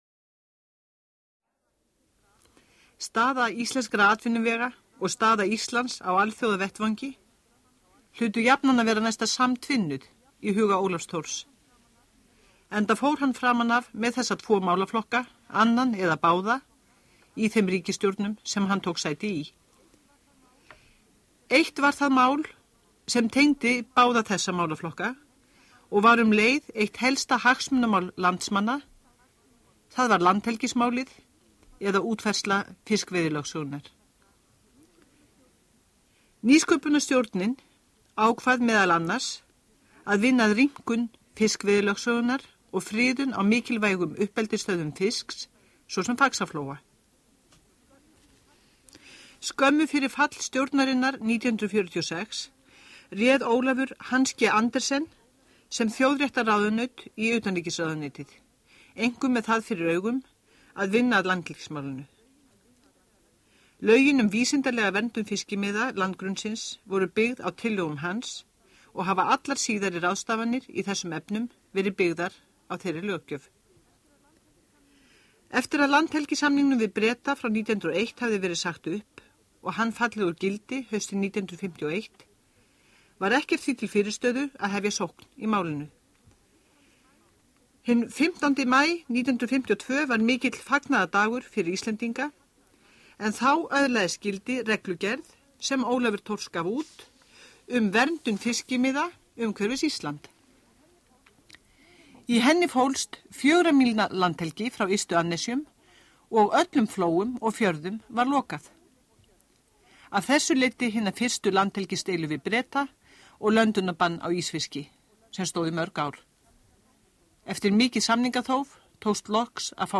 Útvarpsþáttur RÚV
Aldarafmælis Ólafs Thors minnst í Ríkisútvarpinu 19. janúar 1992.